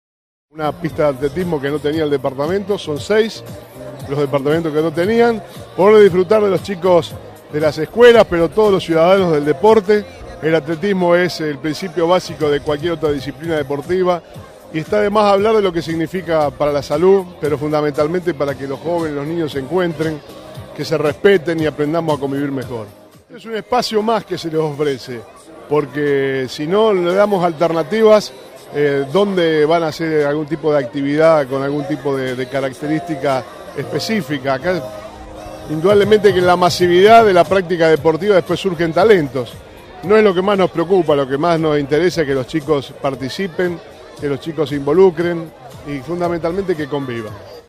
Declaraciones de Bonfatti.